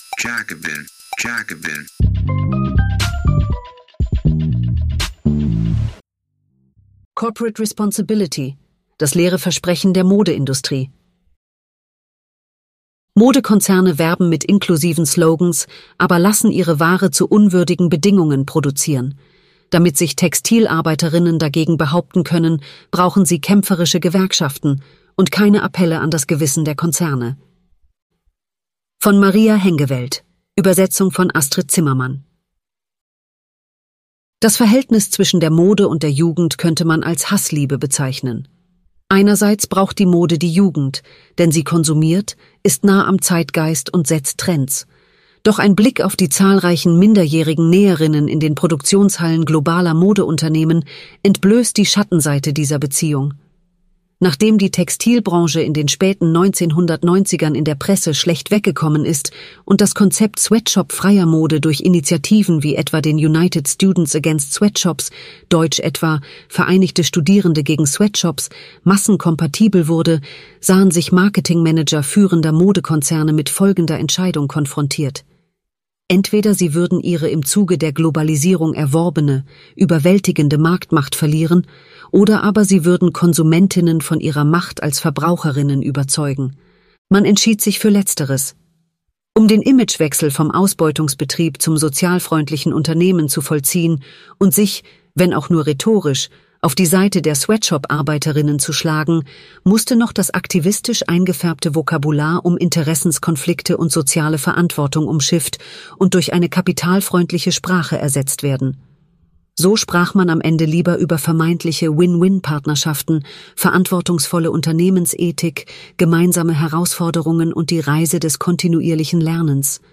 Präsident Tokajew versprach daraufhin umfassende Reformen – doch der politische Aufbruch blieb aus. Interview